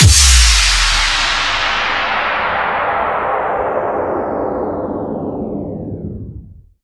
男性发声反应 " 男性窒息2
描述：男性窒息：一个年轻的成年男性咳嗽，窒息，并在窒息时努力呼吸。使用我的Turtlebeach EarforceX12耳机录制，然后在Audacity中进行编辑，听起来更像是男人。
标签： 咳嗽 喘不过气来 人类 呼吸 发声 咳嗽 呛咳 声音
声道立体声